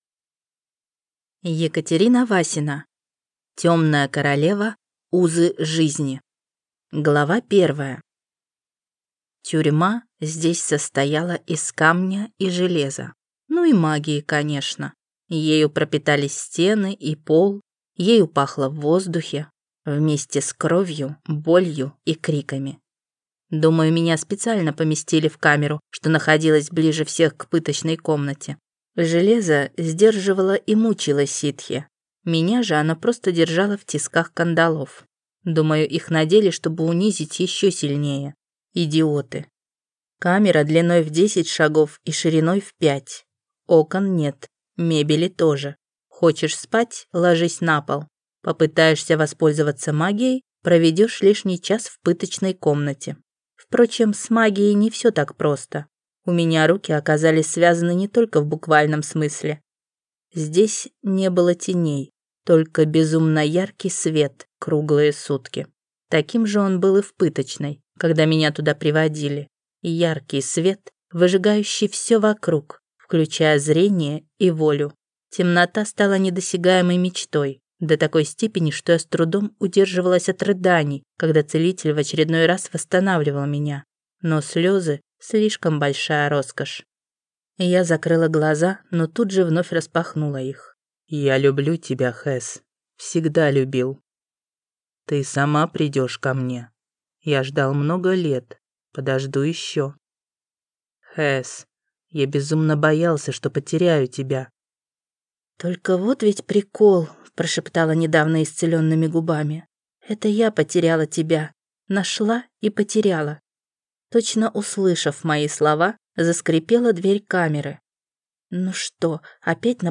Аудиокнига Темная королева. Узы жизни | Библиотека аудиокниг